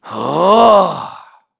Evan-Vox_Casting2.wav